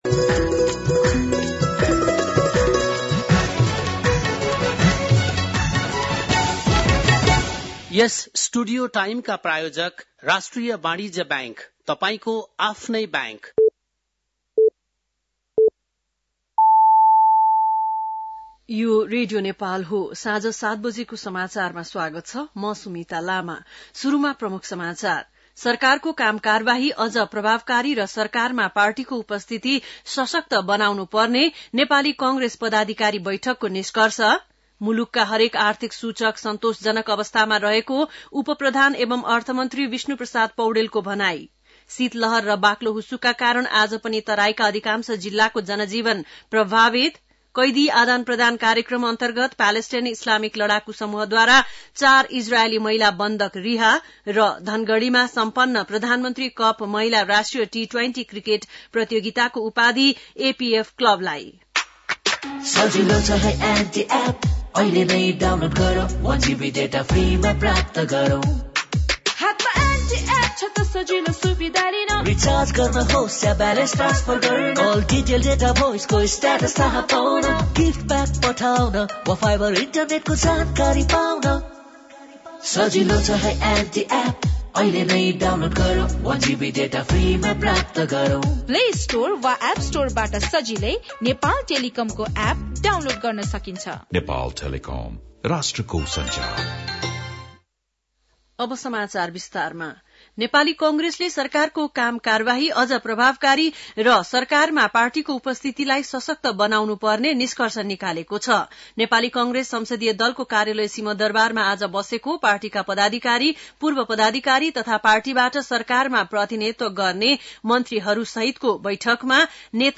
बेलुकी ७ बजेको नेपाली समाचार : १३ माघ , २०८१